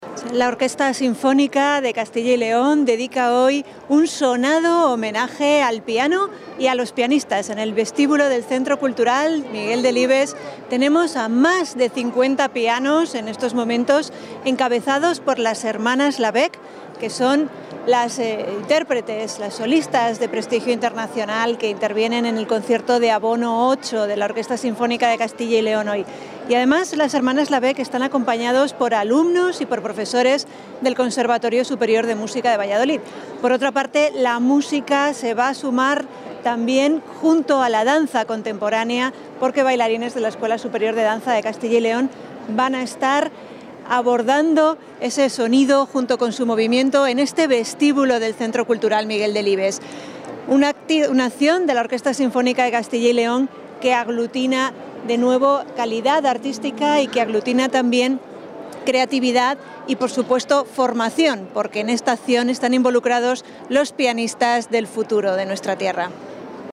Declaraciones de la directora general de Políticas Culturales.